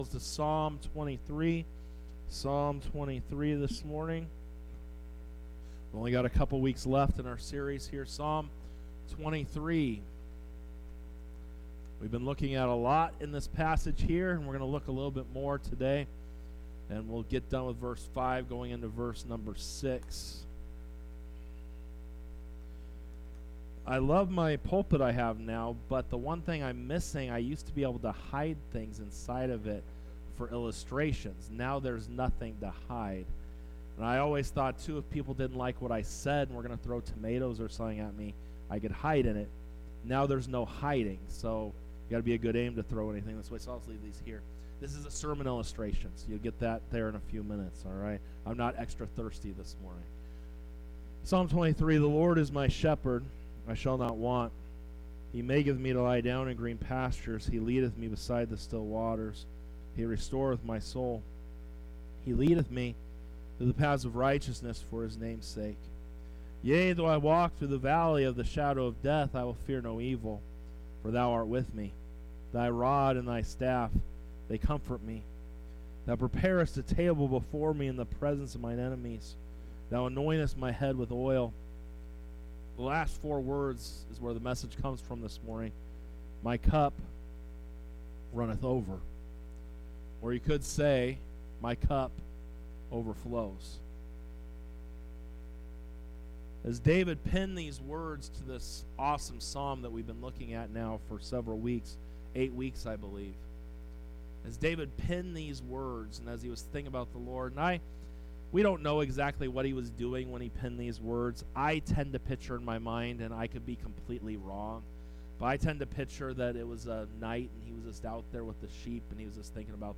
Sermons | Victory Baptist Church
Sunday Worship Service 07:14:24 - From Overwhelmed To Overflowing